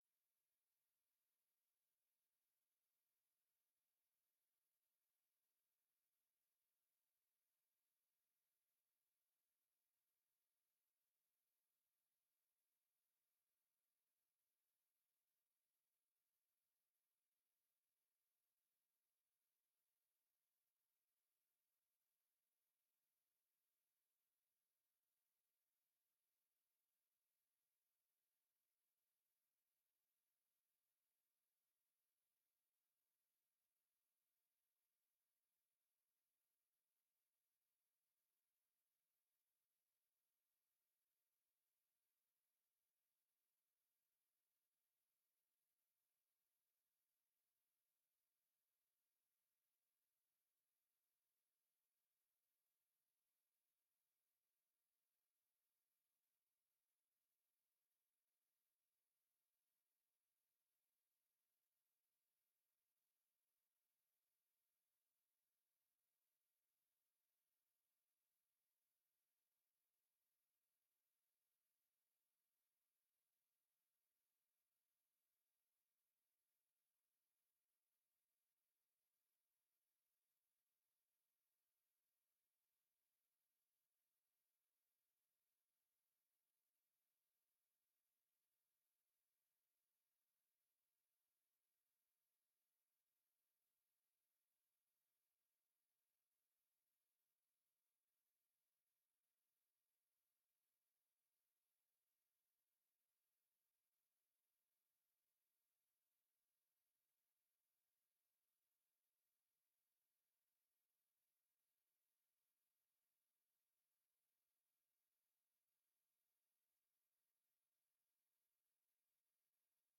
Commissie Burger en bestuur 18 januari 2024 19:30:00, Gemeente Ouder-Amstel
Download de volledige audio van deze vergadering